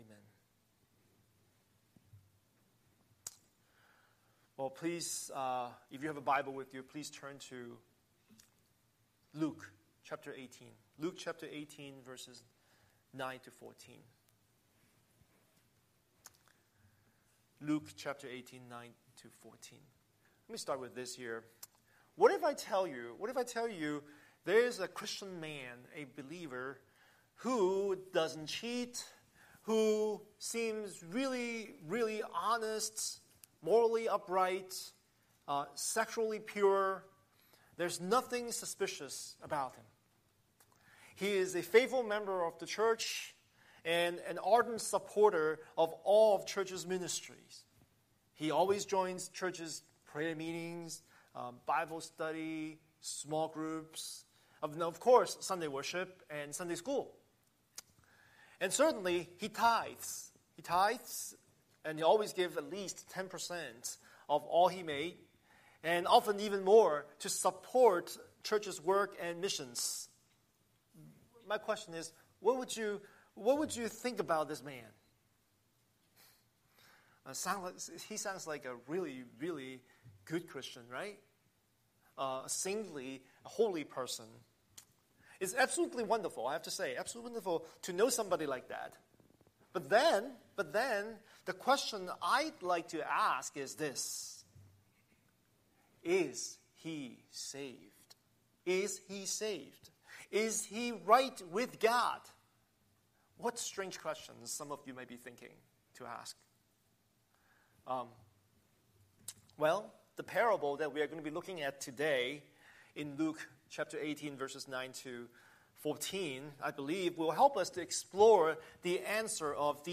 Scripture: Luke 18:9–14 Series: Sunday Sermon